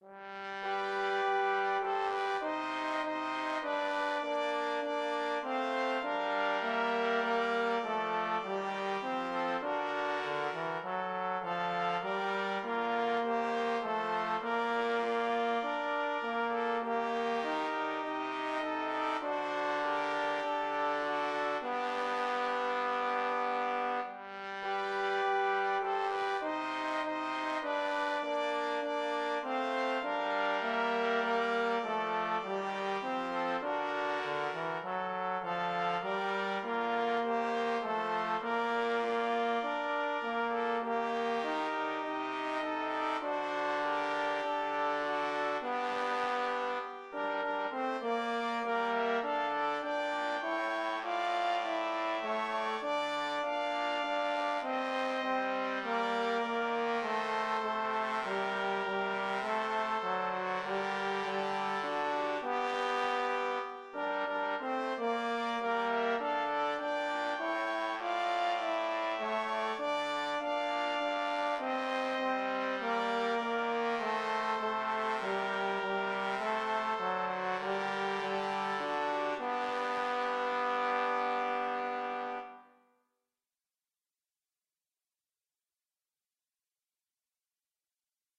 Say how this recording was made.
Virtual performance *#273164